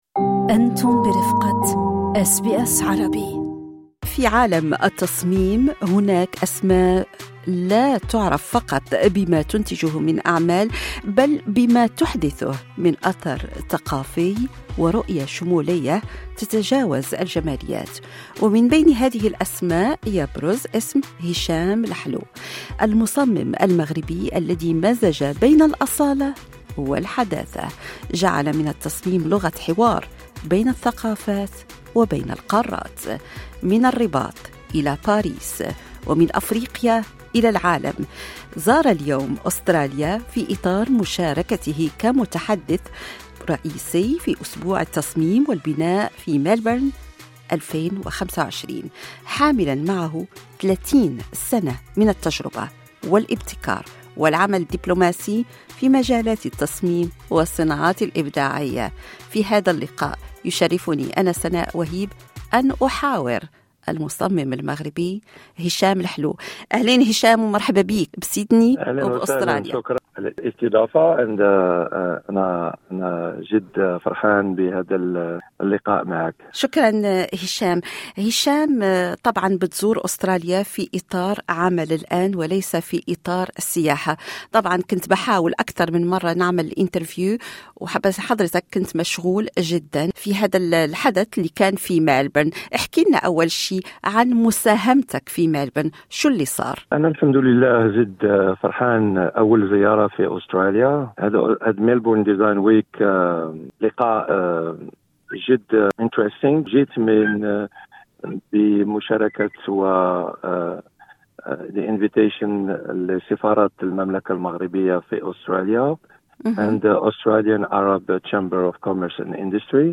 في لقاء مع أس بي أس عربي